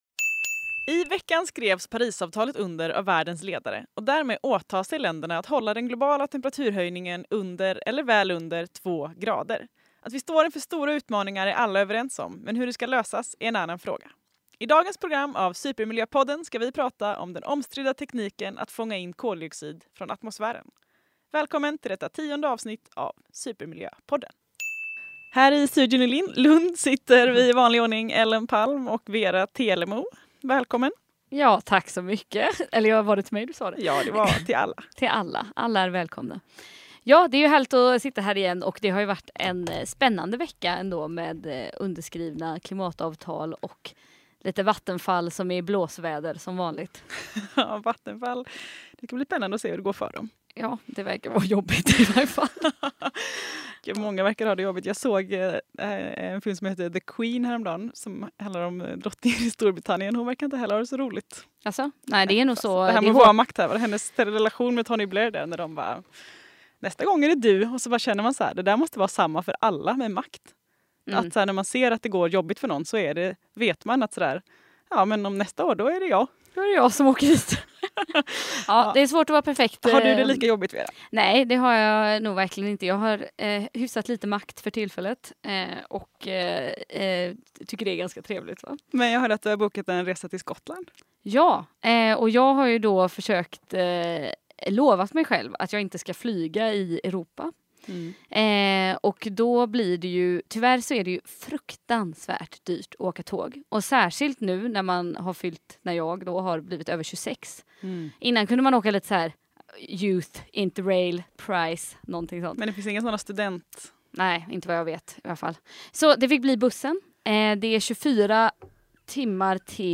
Supermiljöpodden diskuterar den omstridda tekniken att fånga in koldoxid från atmosfären.